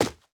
Player_Footstep_09.wav